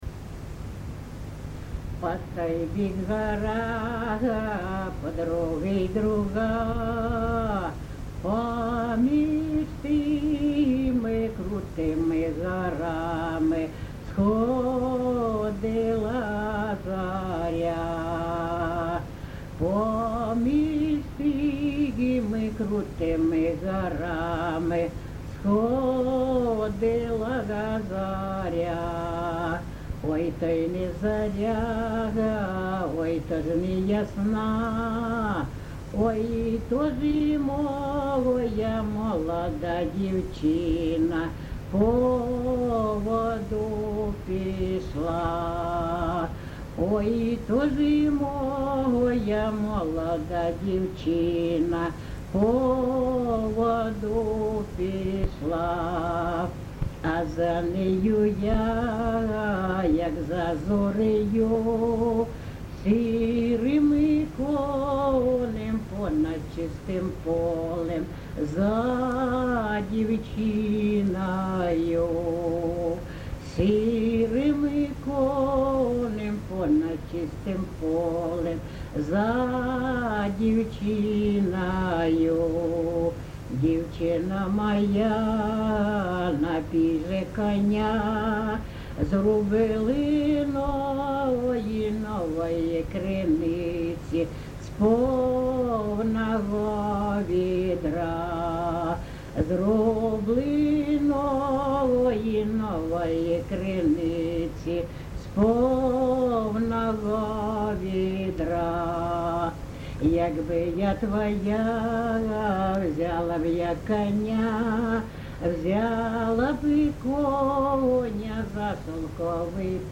ЖанрПісні з особистого та родинного життя
Місце записус. Некременне, Олександрівський (Краматорський) район, Донецька обл., Україна, Слобожанщина